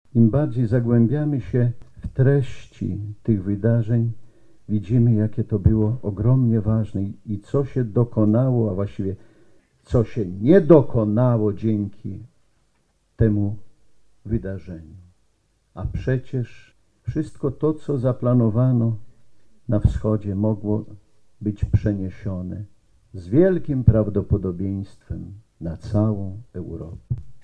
W Ossowie w kościele Matki Bożej przy Cmentarzu Poległych biskup warszawsko-praski Romuald Kamiński odprawił mszę świętą w 98. rocznicę Bitwy Warszawskiej.